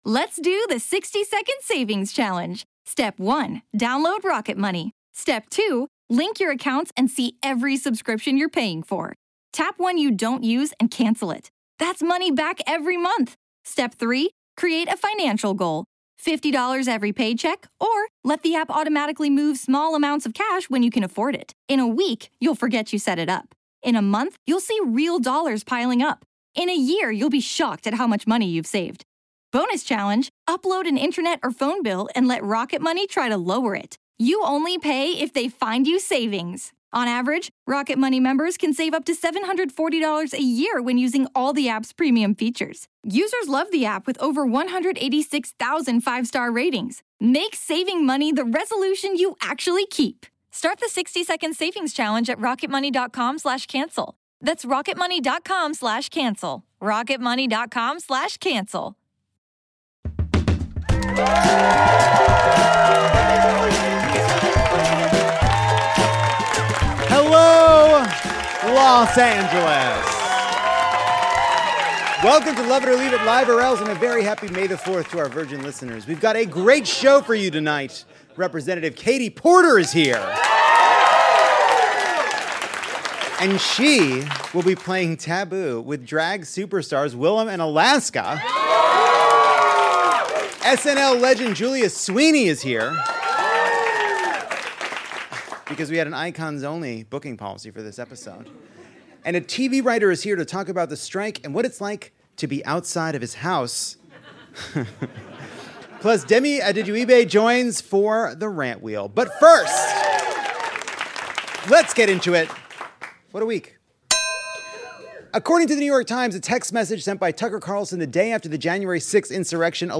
The king of Lovett Or Leave It welcomes his queens to Los Angeles’s beautiful Dynasty Typewriter theater ahead of this coronation weekend. Representative Katie Porter plays monarch in a minivan with “Queen For a Day,” and joins Drag Race’s Alaska and Willam for a royally funny round of Taboo.